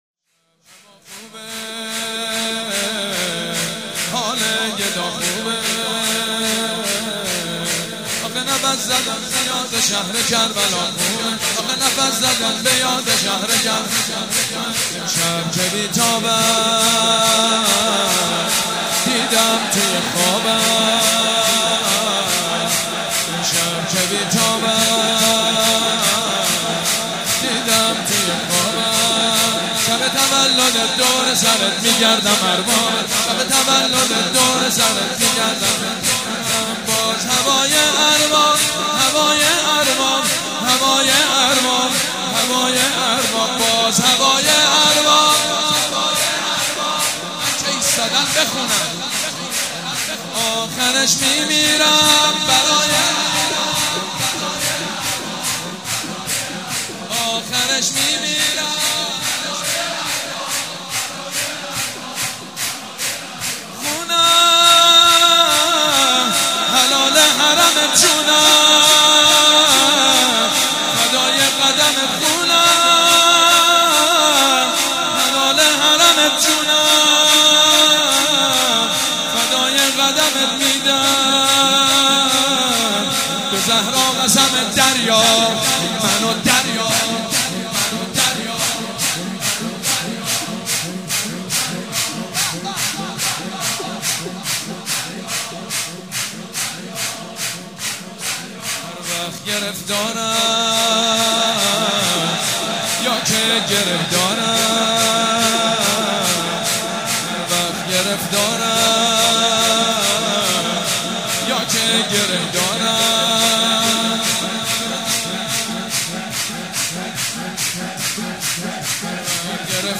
سرود: حال گدا خوبه، آخه نفس زدن به یاد کربلا خوبه